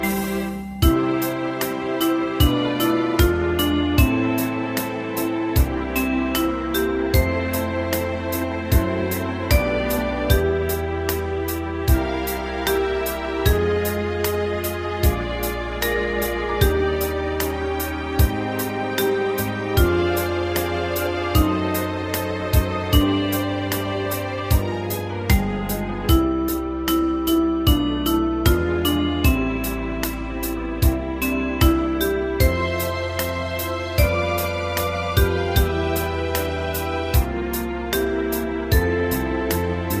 Unison musical score and practice for data.